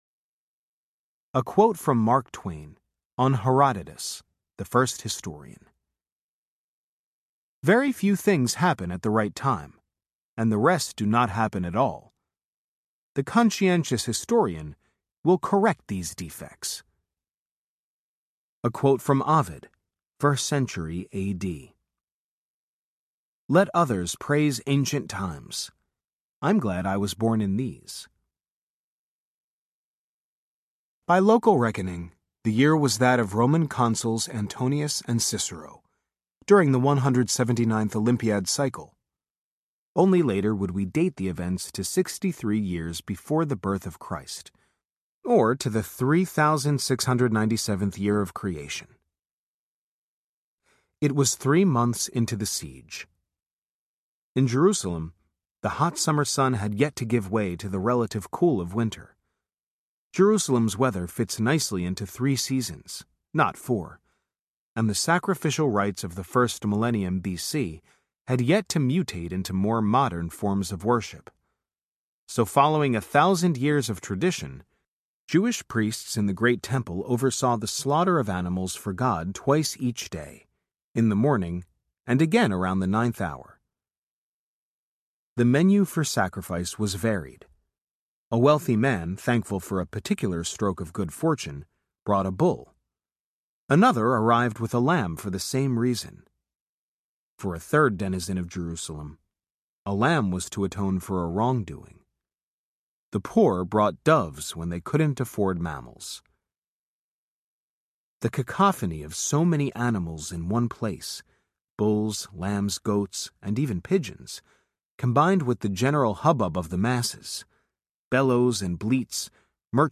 The Bible’s Cutting Room Floor Audiobook
10.5 Hrs. – Unabridged